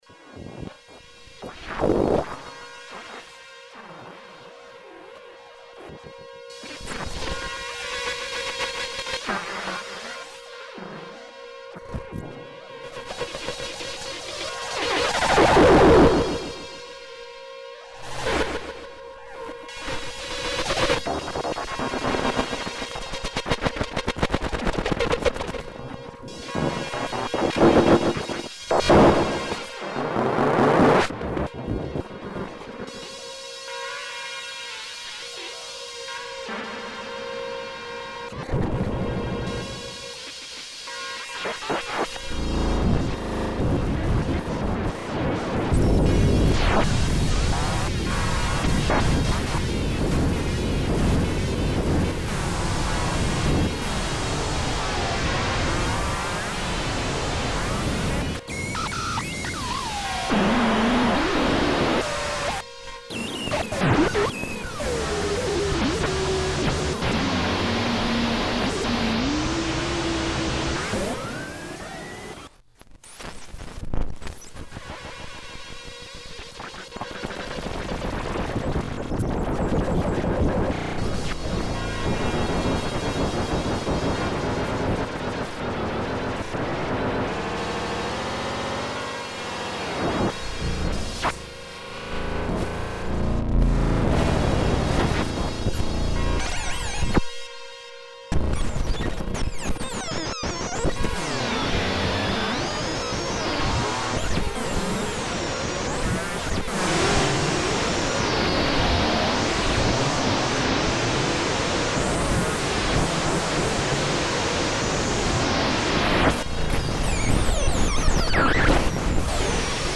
synth track
some of these are nearly 20mg - please watch your volumes there are some very loud sections in these. the samples are just me cutting crude loops of mine and other peoples work, computer - teaspoon - mixer - computer, nothing else. at a few points i plug in the attenuated audio output of a summed pair of sine VCO’s set to low frequencies into the CV input to simulate the audio outputs of a standard sound card, no other outside control is used. i try to cover as much range as i can without stopping on anything for too long so you will have to use your imagination to pause things a bit, there is a lot to cover. most tracks have the right side clean so you can compare the two. most of the time is spent with the SZ, FDBK, LNGTH and THRSH controls, the push button is only used a few times, tilt disabled.